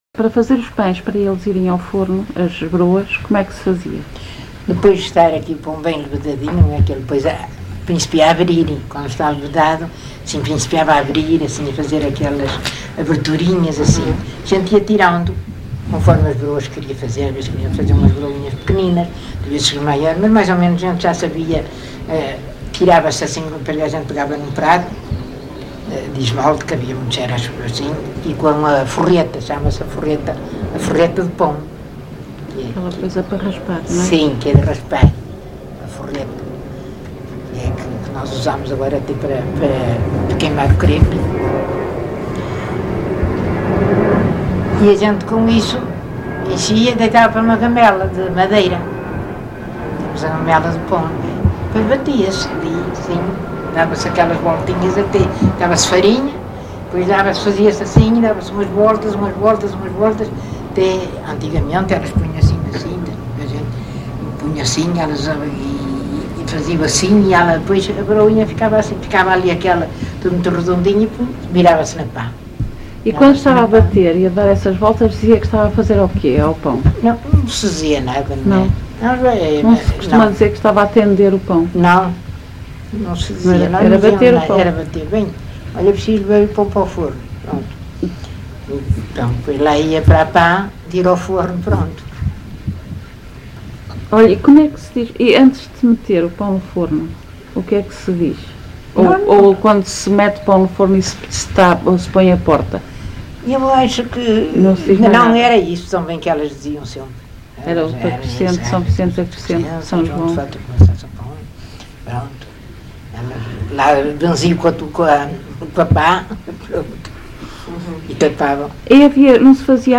LocalidadeGião (Vila do Conde, Porto)